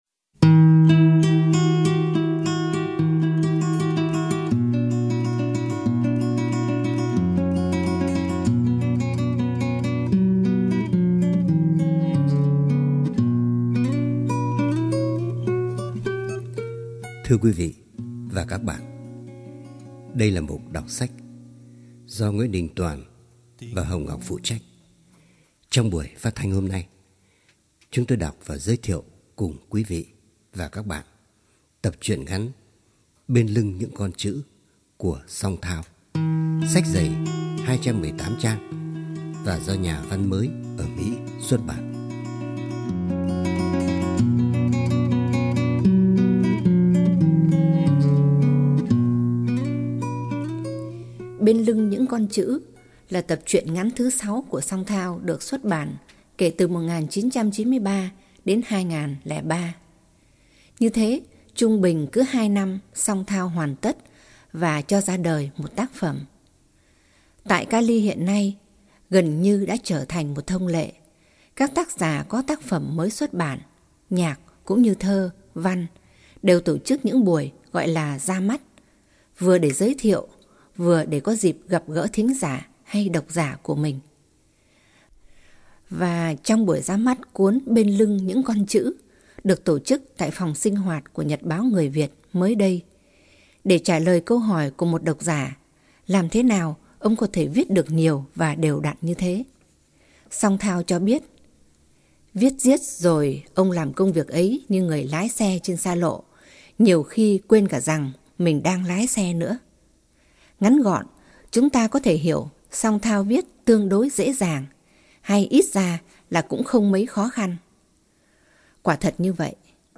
Giọng đọc